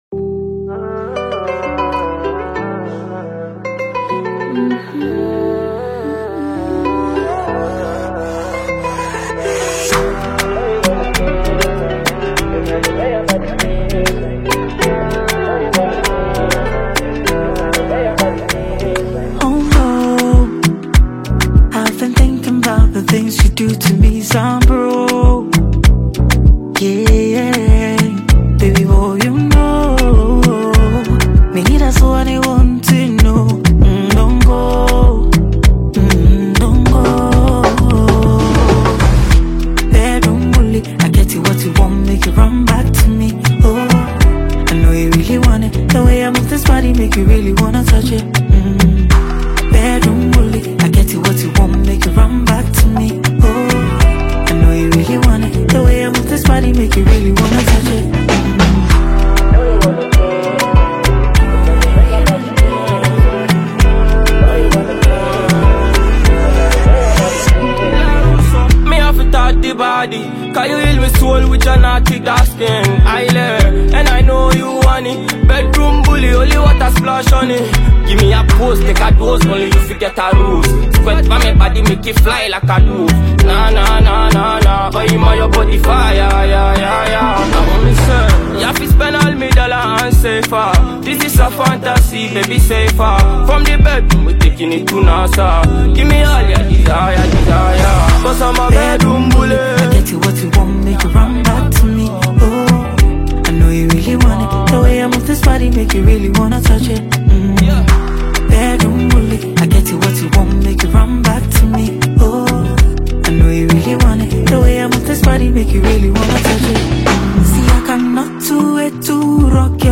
Genre: Afrobeats / Dancehall